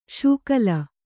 ♪ sūkala